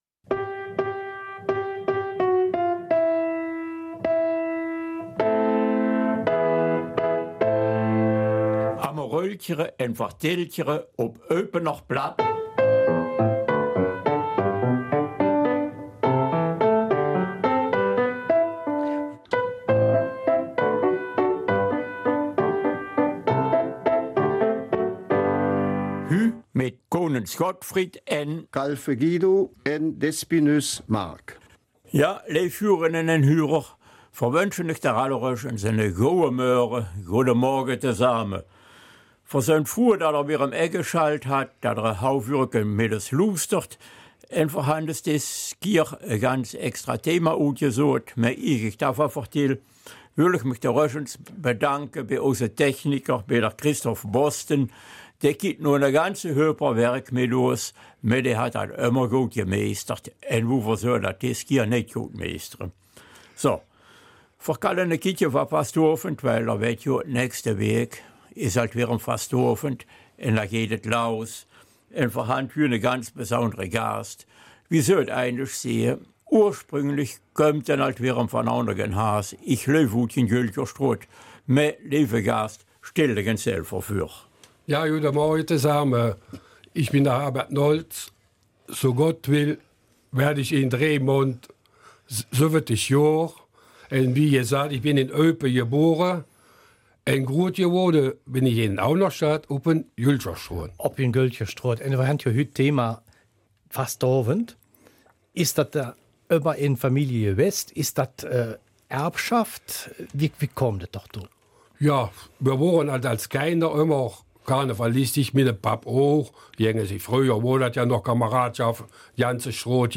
Eupener Mundart